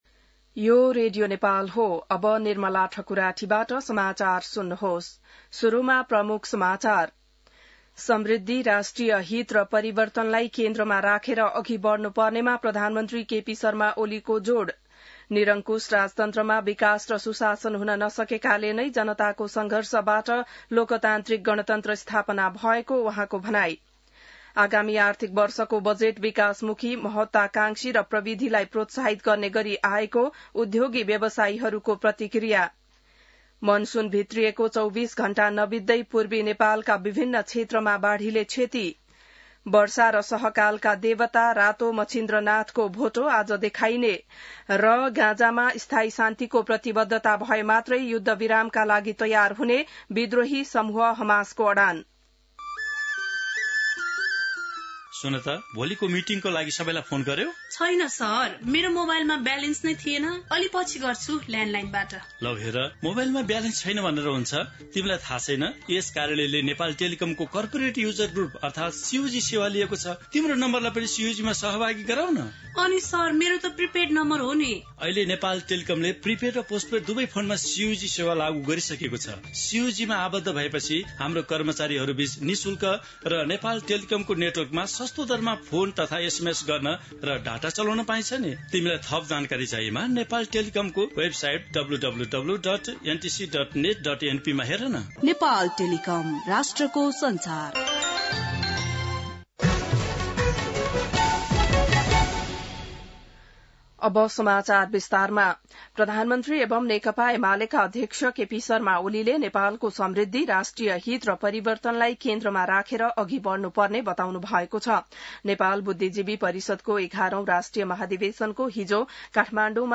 बिहान ७ बजेको नेपाली समाचार : १८ जेठ , २०८२